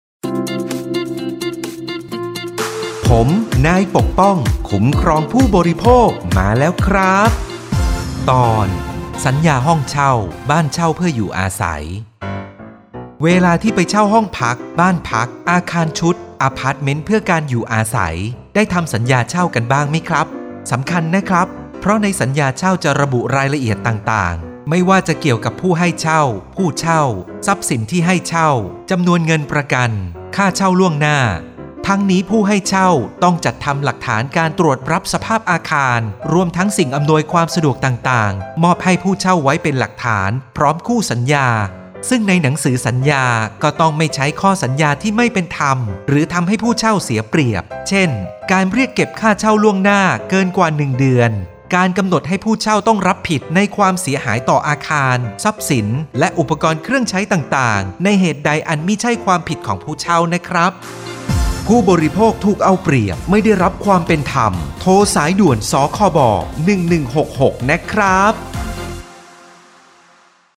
สื่อประชาสัมพันธ์ MP3สปอตวิทยุ ภาคกลาง
008.สปอตวิทยุ สคบ._ภาคกลาง_เรื่องที่ 8_.mp3